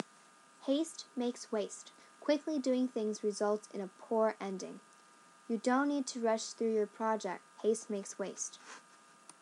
英語ネイティブによる発音は下記をクリックしてください。